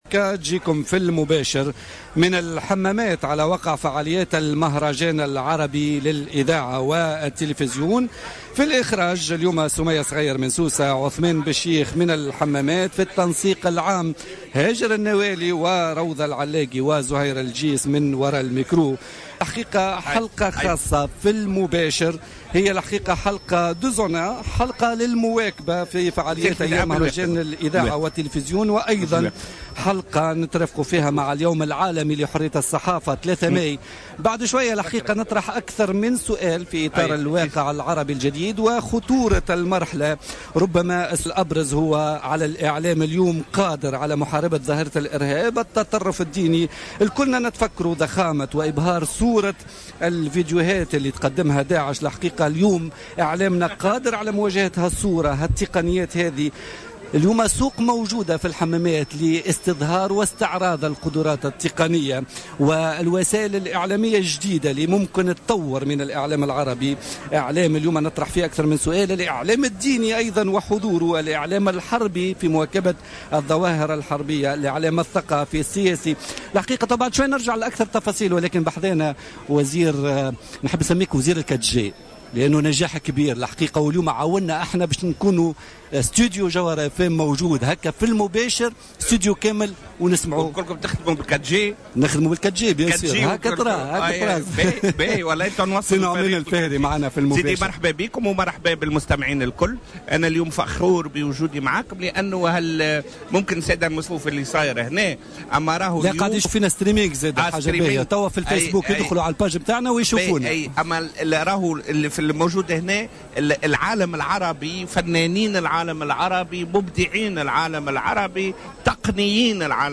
قال وزير تكنولوجيات الإتصال والإقتصاد الرقمي نعمان الفهري ضيف بوليتيكا اليوم الثلاثاء 3 ماي 2016 على هامش فعاليات المهرجان العربي للإذاعة والتلفزيون أن الحمامات تستضيف اليوم أبرز فناني العالم العربي وتقنييه ومنتجيه وهي مناسبة هامة يمكن ان تعطي لتونس اشعاعا حول العالم بأسره على حد قوله.